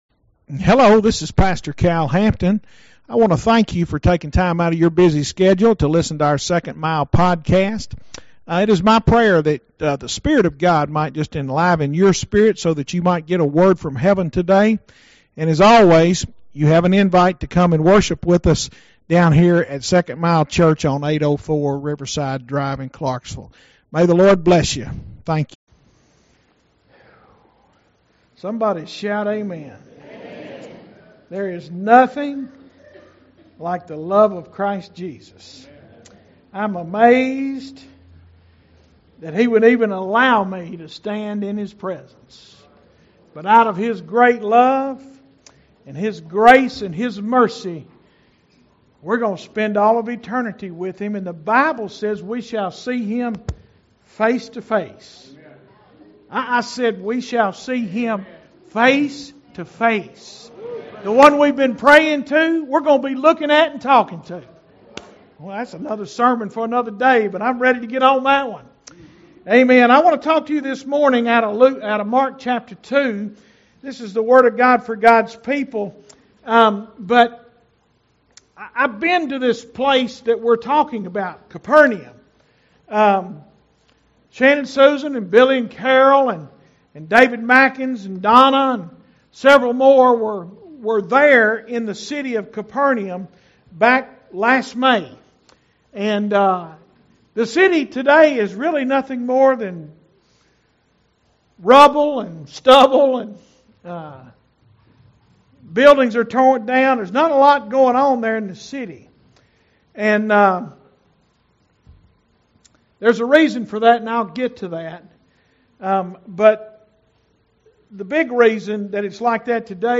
SERMONS - 2nd Mile Church